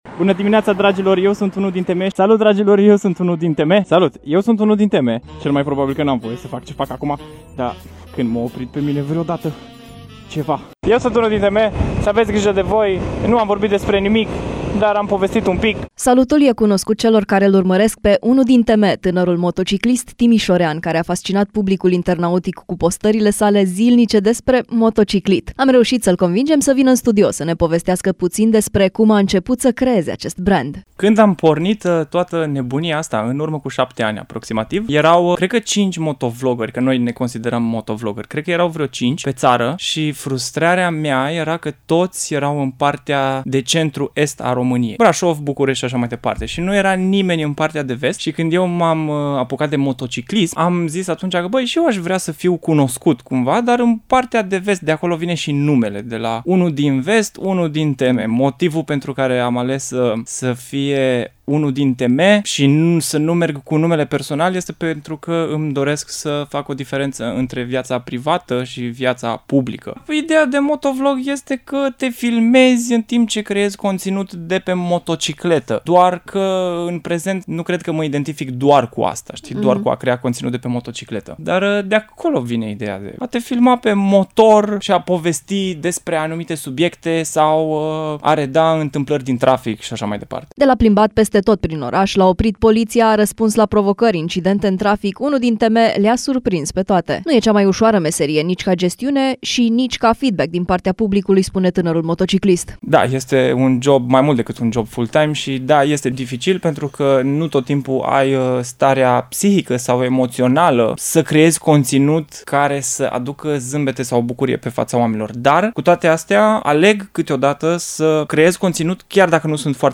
Am reușit să-l convingem să vină în studio să ne povestească puțin despre cum a început să creeze acest brand.